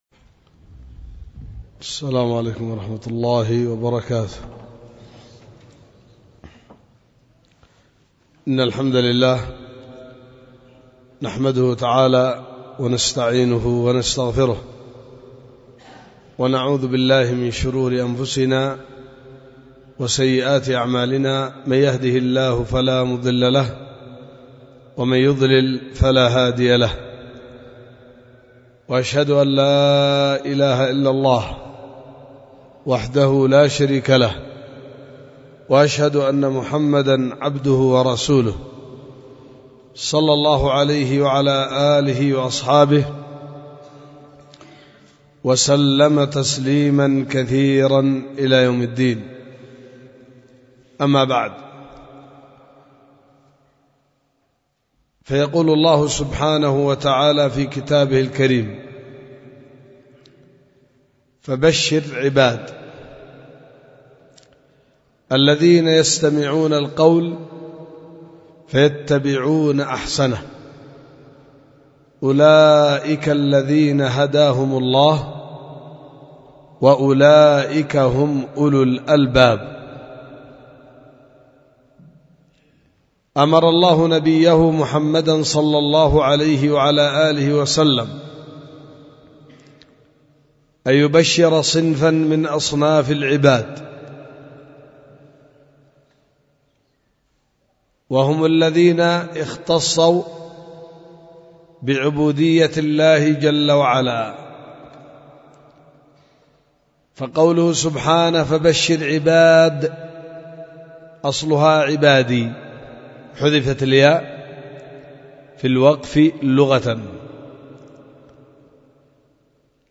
محاضرة
في دار الحديث بوادي بنا – السدة – إب – اليمن